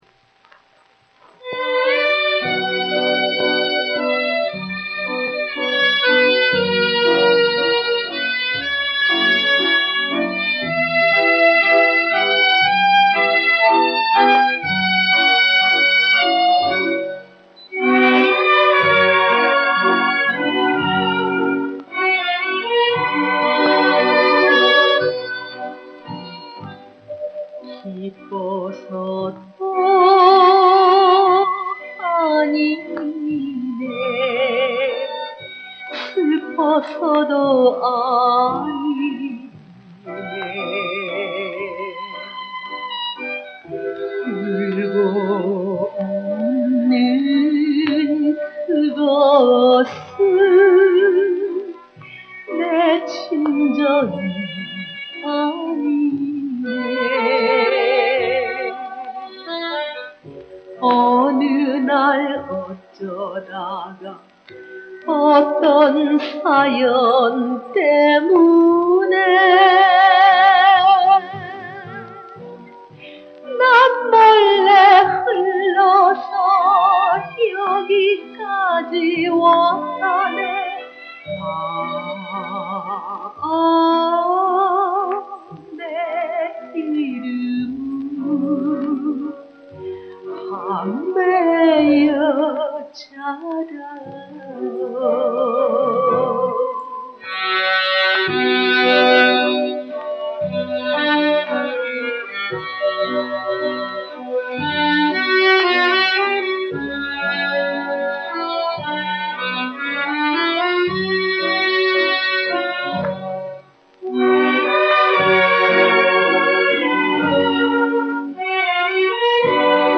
※ 이 음반 구입 복각한 음원입니다